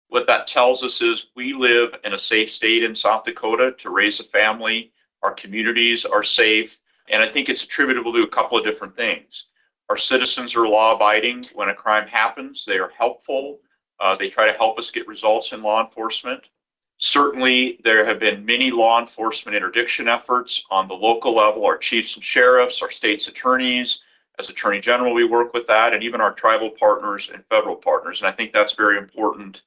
PIERRE, S.D.(HubCityRadio)- On Thursday, Attorney General Marty Jackley gave a report on crime rates in South Dakota for 2025.